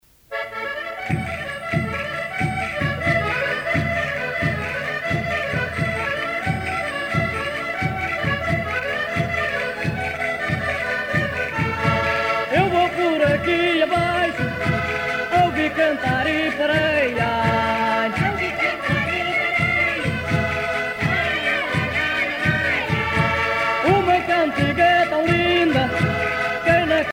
danse : vira (Portugal)
Grupo folclorico da Casa do Concelho de Arcos de Valdevez
Pièce musicale éditée